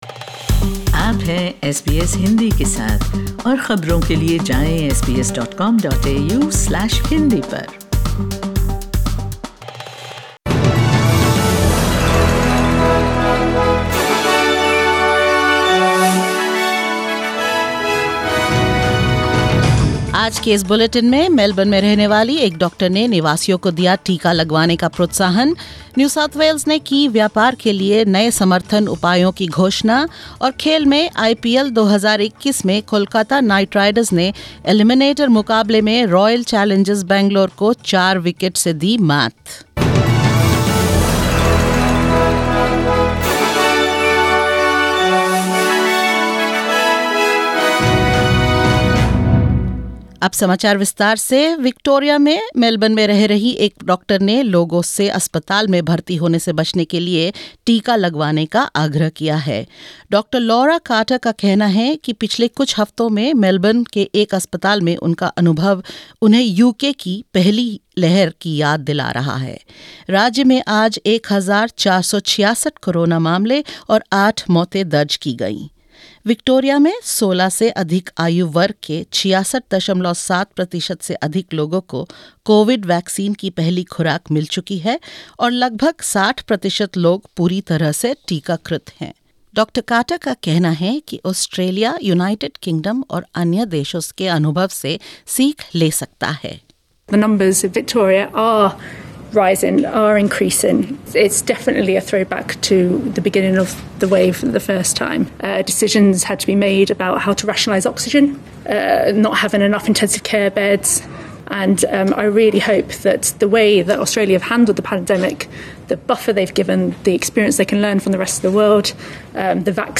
In this latest SBS Hindi News bulletin of Australia and India: New South Wales Premier Dominic Perrottet announces new business support measures for the state; Victoria records low COVID-19 numbers with 1,466 cases; New federal government world-first national strategy to protect the mental health of children under the age of 12 and more.